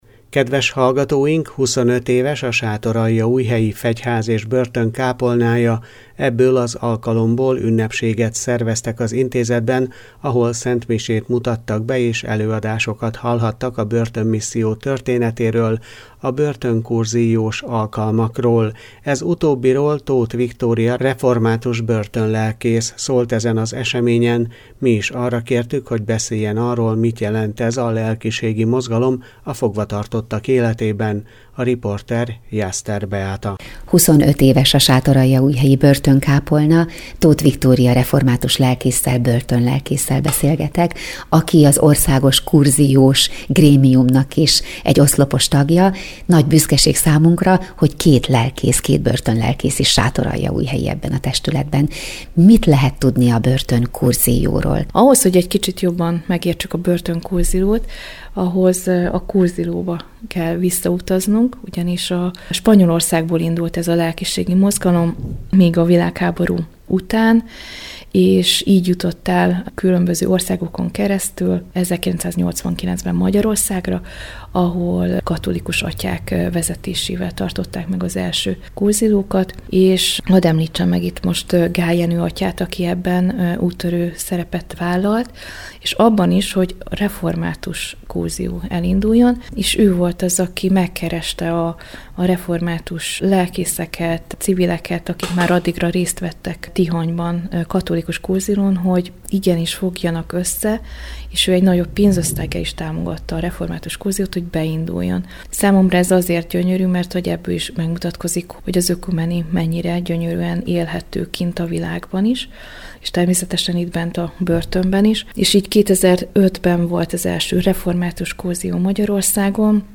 25 éves a Sátoraljaújhelyi Fegyház és Börtön kápolnája. Ebből az alkalomból ünnepséget szerveztek az intézetben, ahol szentmisét mutattak be, és előadásokat hallhattak a börtönmisszió történetéről, a börtöncoursillos alkalmakról.